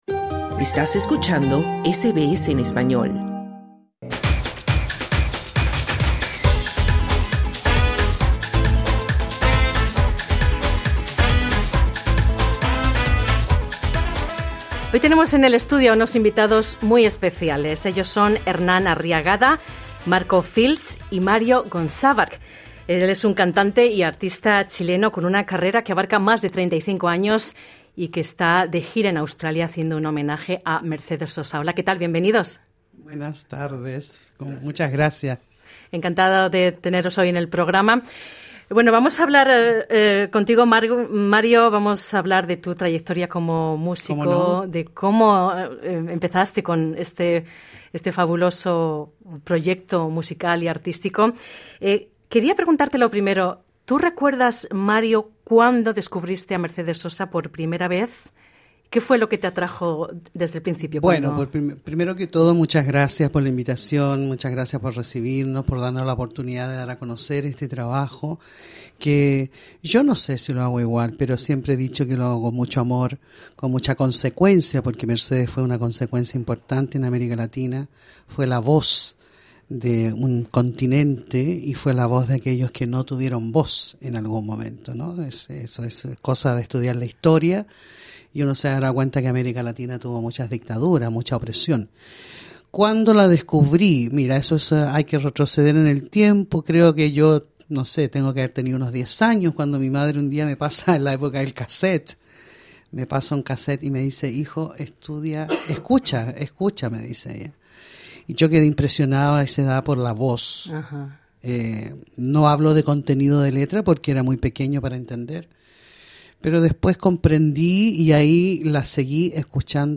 en los estudios de SBS Spanish en Sídney